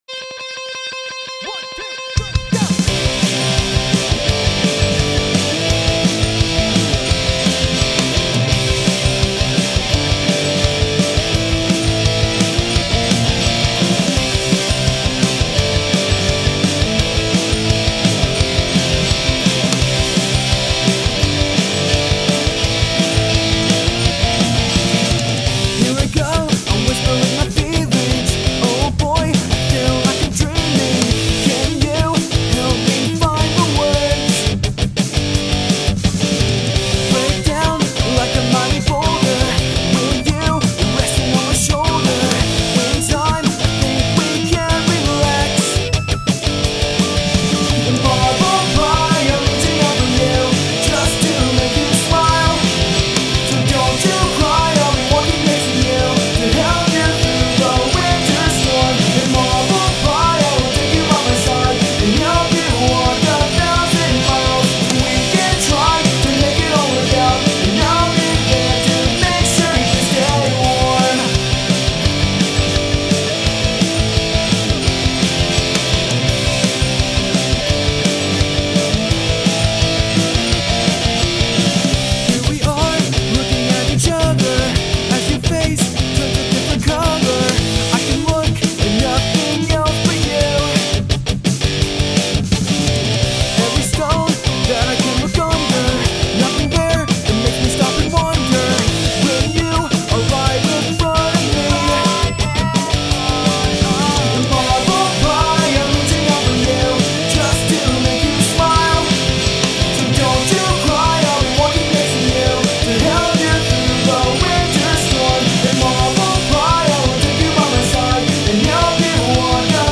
Also my first actual punk track in a while!!!